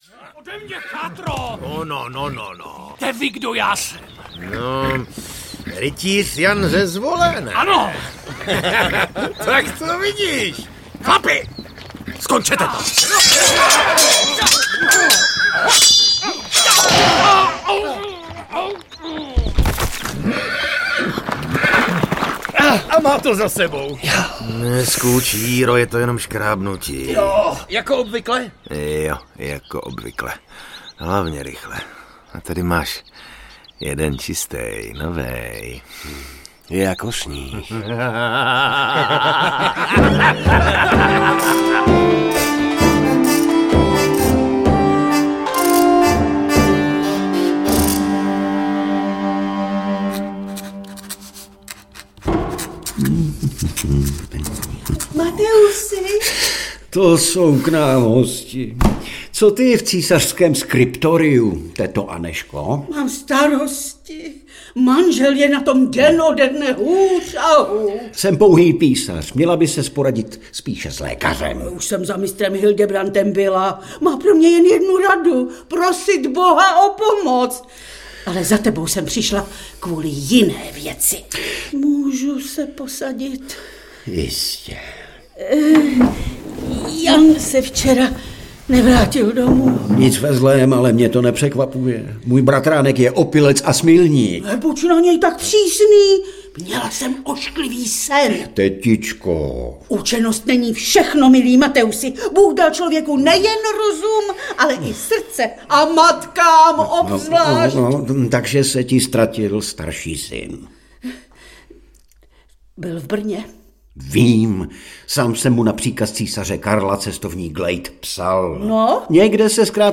Krvavý hejtman audiokniha
Ukázka z knihy